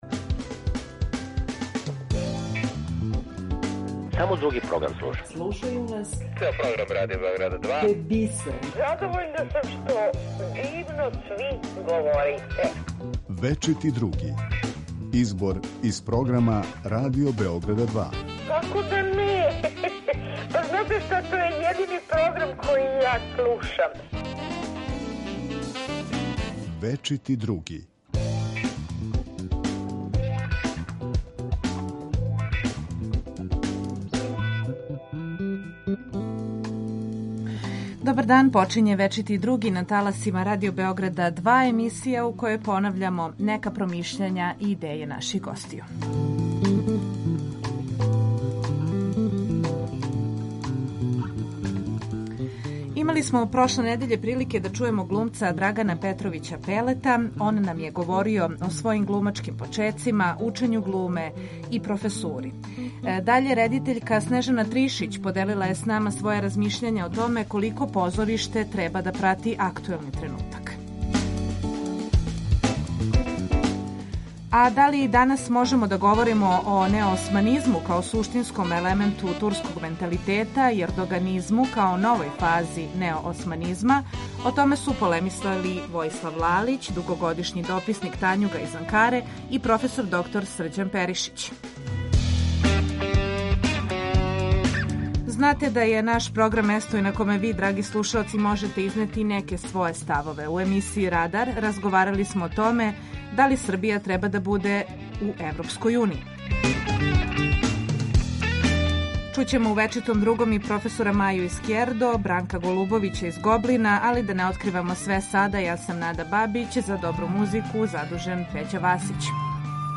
Из докуменатрног програма издвајамо репортажу о магазину ,,Лице улице".